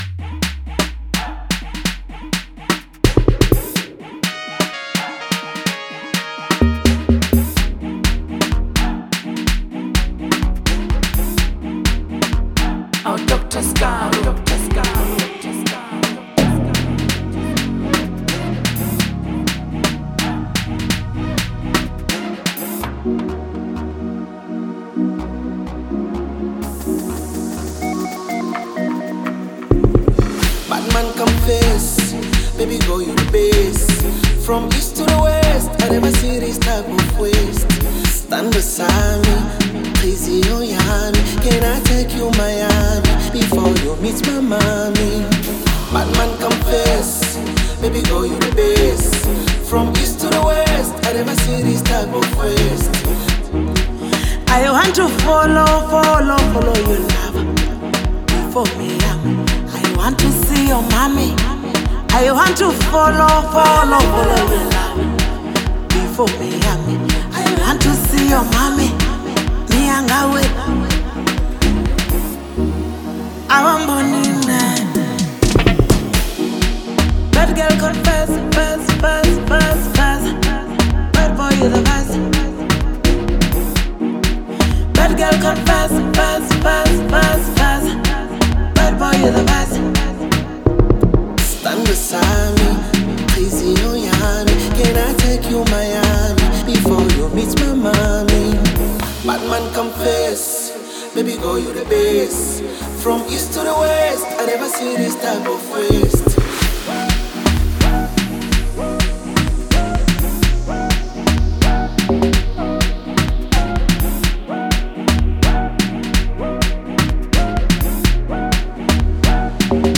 signature Afro-house and energetic vibes
smooth Bongo Flava sound
powerful vocals with her unique African rhythm
melodic Swahili verses